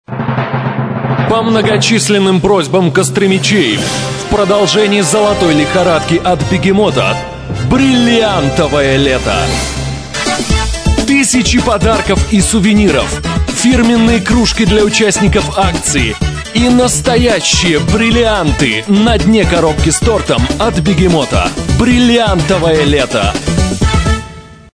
Рекламные радио ролики записаны в формате mp3 (64 Kbps/FM Radio Quality Audio).